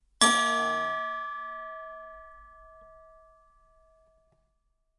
描述：鸡尾酒调酒器与茶匙坠毁。 （连音）
Tag: Tascam的 贝尔 DR-05